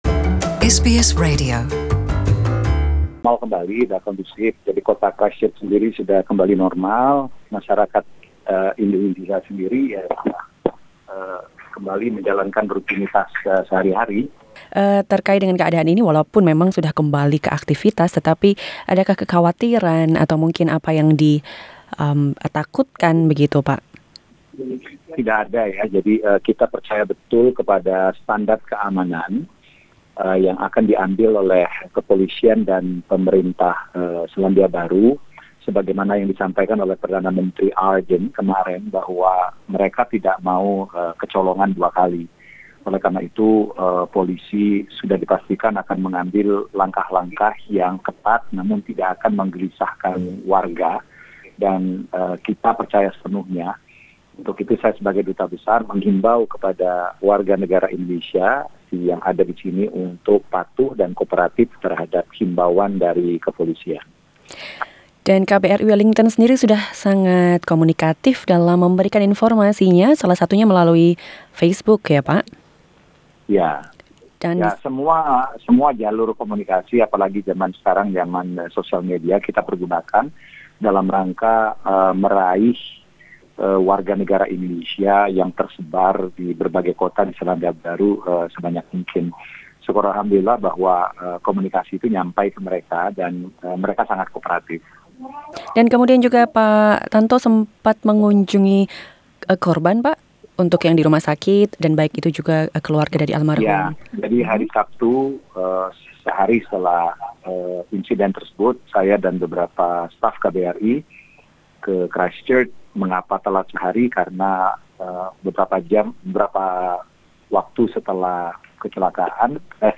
Duta Besar Indonesia untuk Selandia Baru, Samoa dan Kerajaan Tonga, Tantowi Yahya, berbicara pada SBS Indonesian terkait situasi di Selandia Baru menyusul serangan teror di masjid di Christchurch.